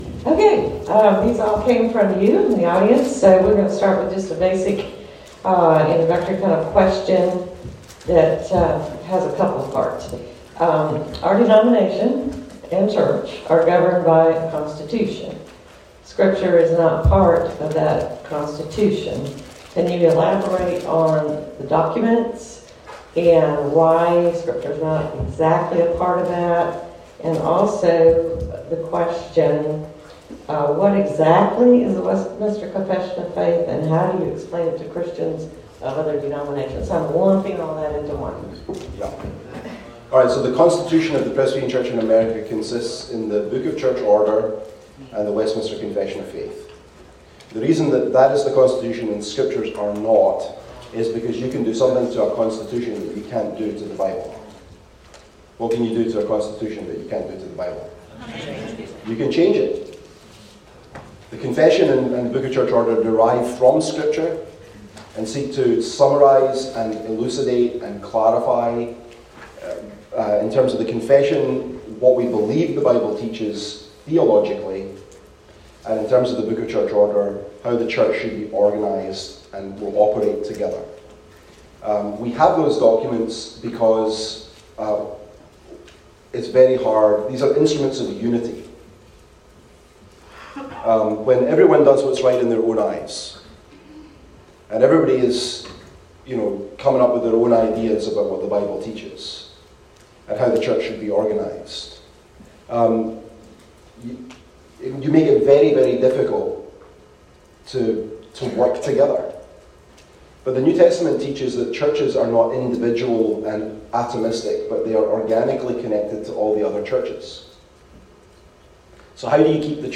The-Doctrine-of-Scripture-QA-session.mp3